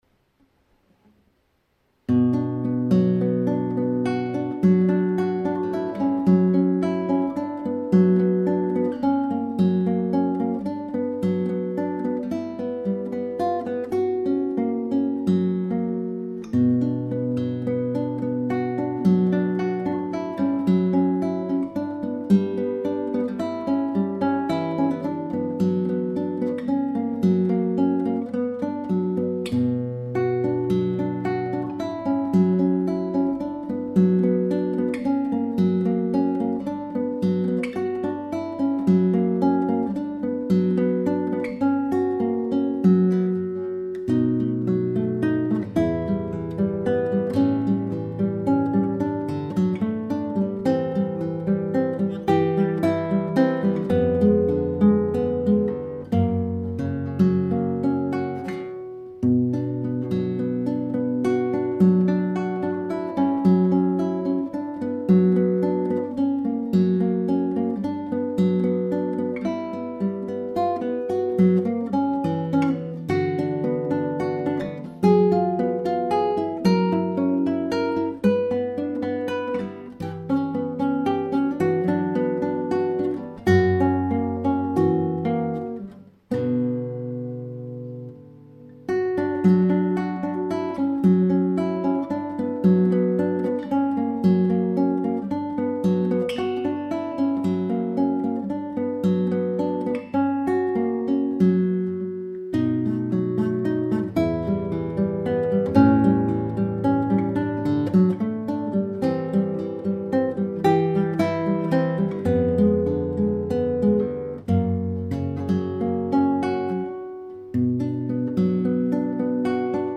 [古典吉他视听] 月光
录了近百遍，没有一遍从头到尾都流畅的，功力着实不足！
zoom h1录的
谢谢！我自己感觉弹得很机械，都听不出主旋律在哪里，哪里该强哪里絯弱都不知道区分。还有右手拨弦用力也很不均匀。
节奏有些赶，强弱不明显，曲目意境表达不够，可以听听双吉他合奏版本找找感觉。